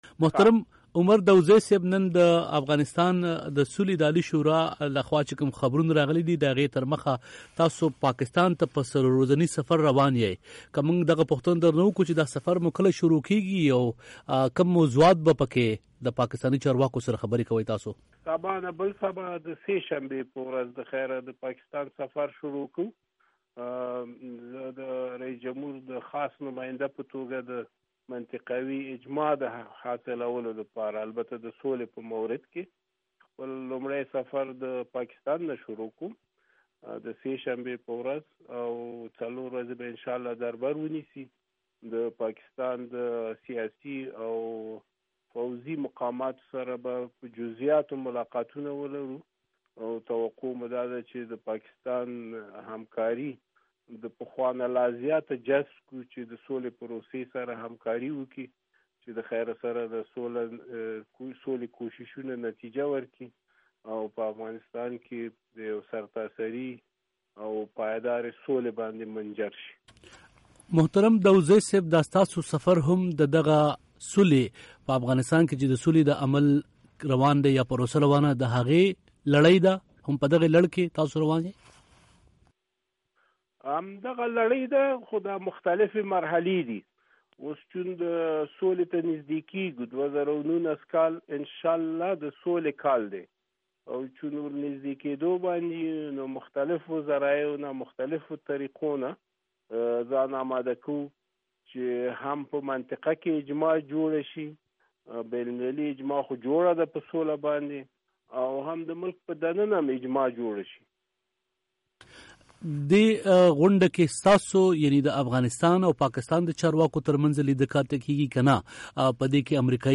ښاغلي داودزي د وي او ای ډیوه سره په یوه ځانگړې مرکه کې وویل: